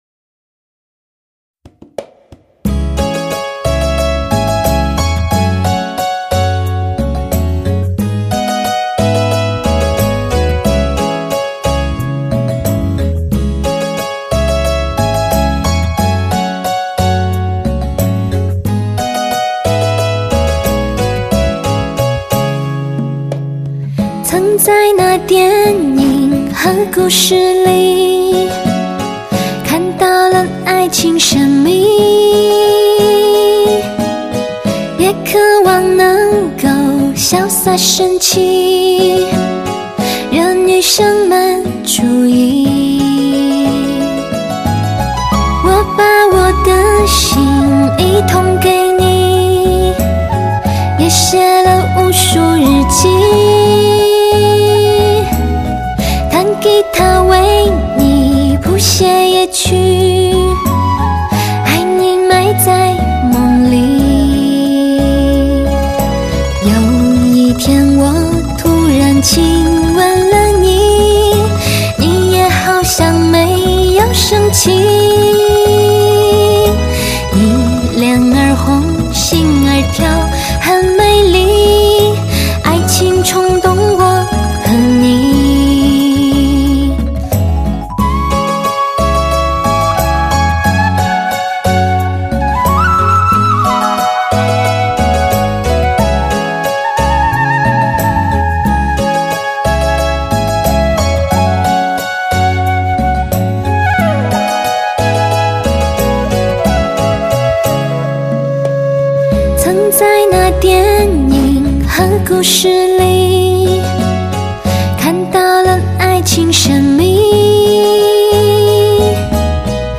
类型: 汽车音乐